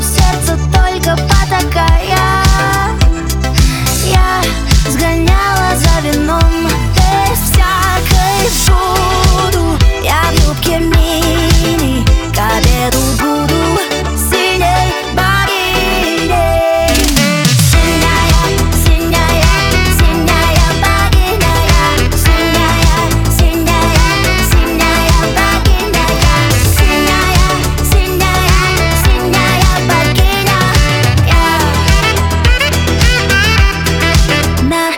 Жанр: Русская поп-музыка / Рок / Русский рок / Русские